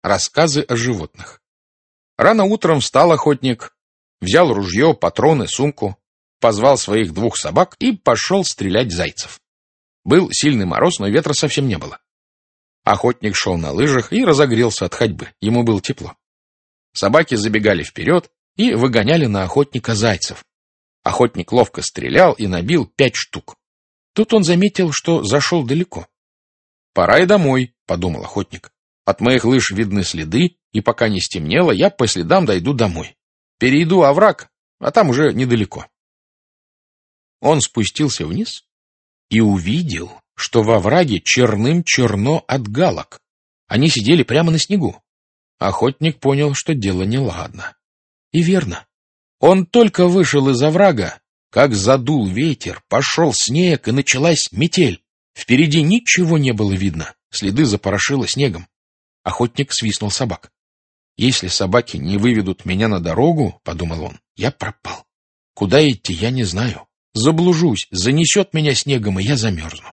Аудиокнига Рассказы о животных | Библиотека аудиокниг
Aудиокнига Рассказы о животных Автор Борис Житков Читает аудиокнигу Александр Клюквин.